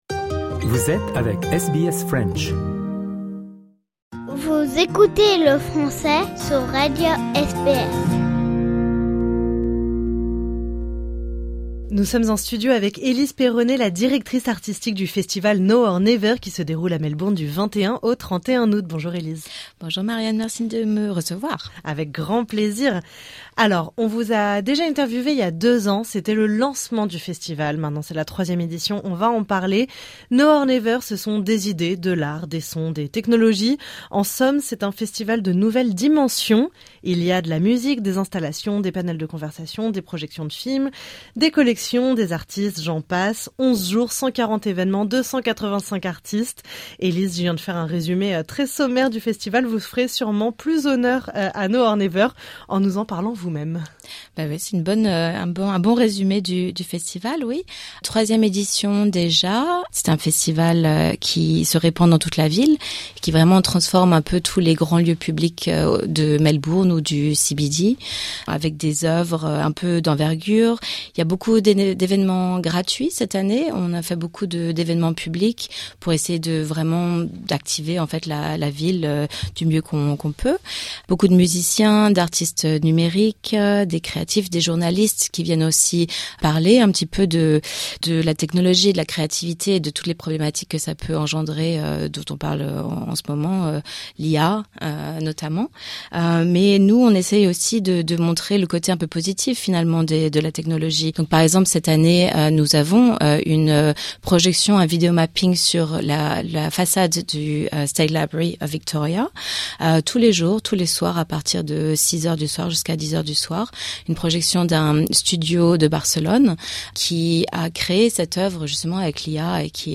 dans les studios de SBS Melbourne